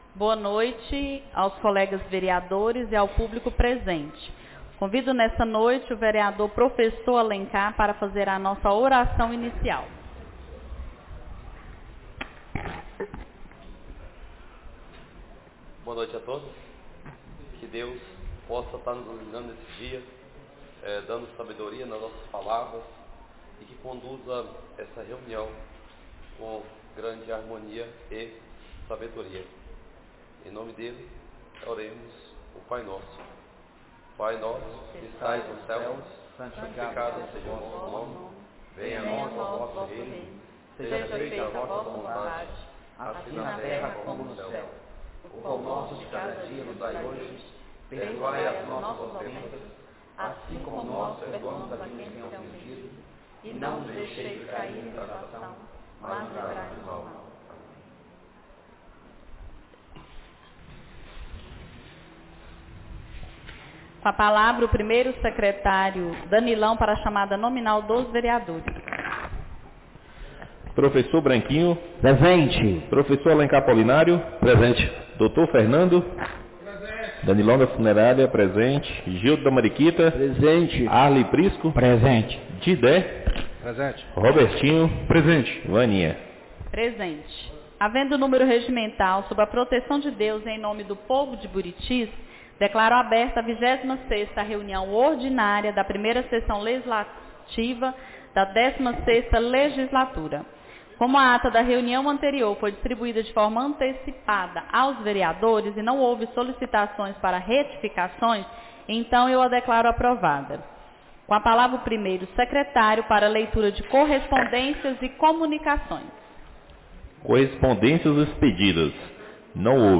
26ª Reunião Ordinária da 1ª Sessão Legislativa da 16ª Legislatura - 11-08-25